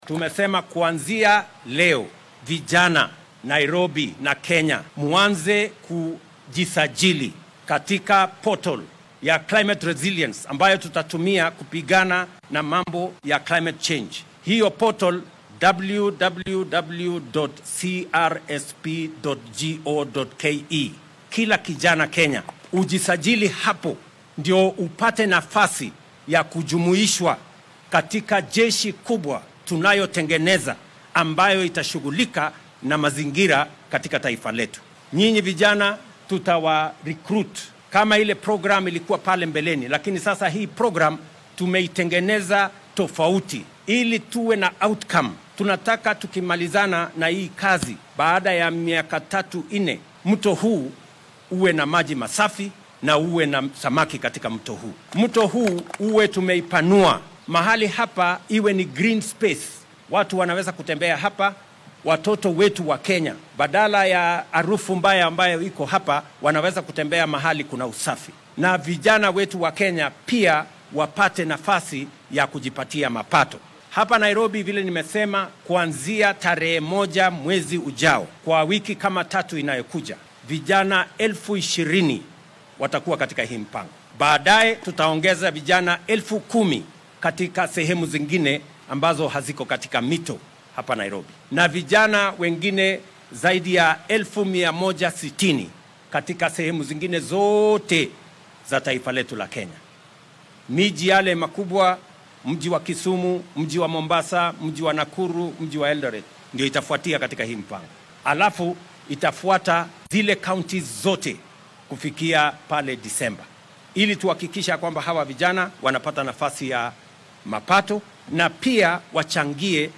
Madaxweynaha dalka William Ruto ayaa ballan qaaday in muddo hal sano gudaheeda ah lagu nadiifin doono webiga Nairobi River. Xilli uu maanta ku sugnaa xaafadda Korogocho ee caasimadda dalka ayuu madaxweynaha sheegay in howshan loo fulin doono wajiyo kala duwan.